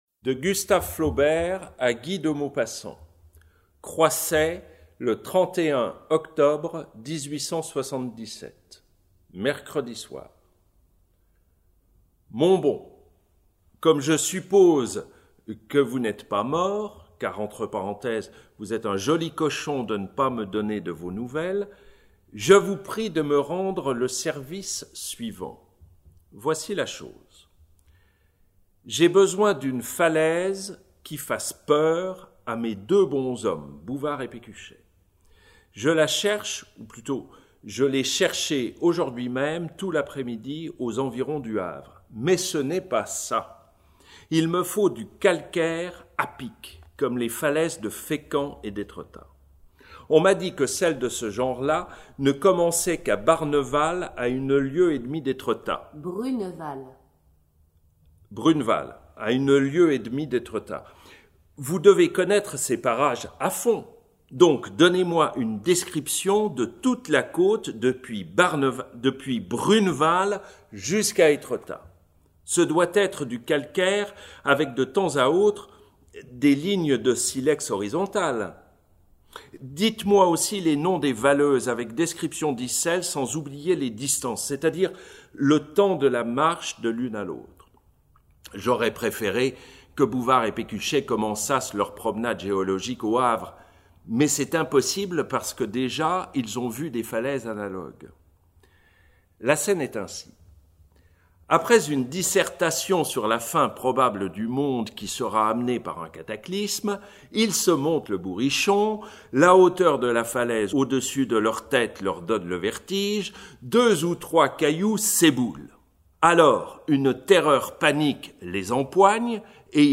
Lecture
des correspondances entre Gustave Flaubert et Guy de Maupassant pour le festival Terres de paroles